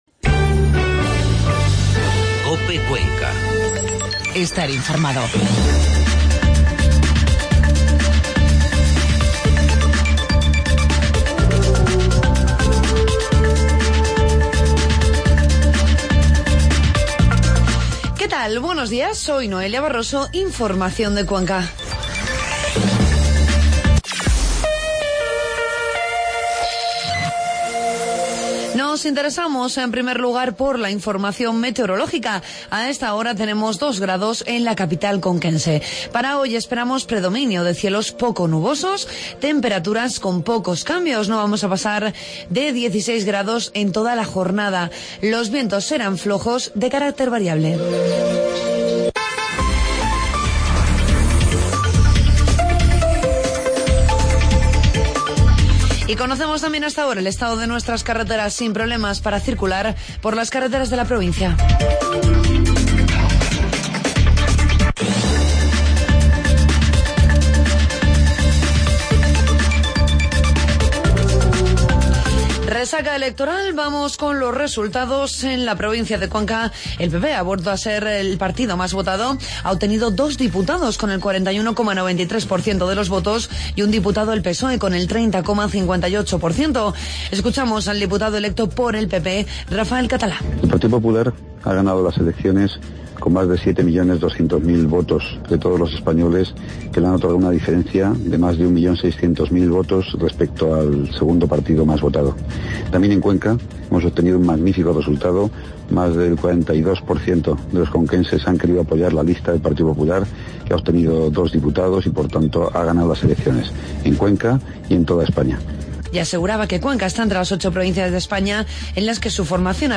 Informativo matinal COPE Cuenca